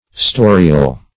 storial - definition of storial - synonyms, pronunciation, spelling from Free Dictionary Search Result for " storial" : The Collaborative International Dictionary of English v.0.48: Storial \Sto"ri*al\, a. Historical.